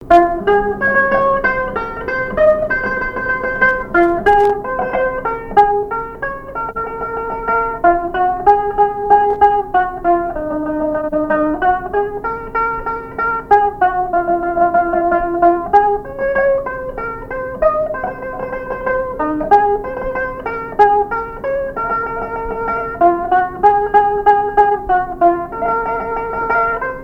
Genre strophique
répertoire au violon et à la mandoline
Pièce musicale inédite